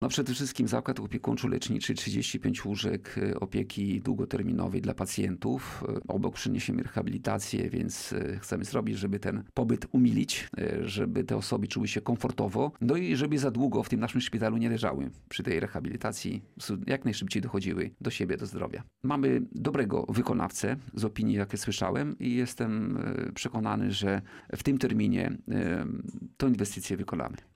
Chcemy żeby pacjenci czuli się komfortowo i jak najszybciej dochodzili do zdrowia – zapewnia starosta Tadeusz Klama.
Tadeusz-Klama-o-budowie-Zakladu-Opiekunczo-Leczniczego.mp3